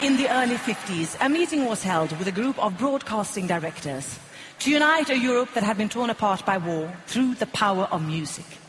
Of course it wasn’t all Americanization. Eight of the 26 songs in the final weren’t in English at all, and host-in-chief Petra Mede (a comedian, not a pop-rock artist) presented the show in decidedly British English: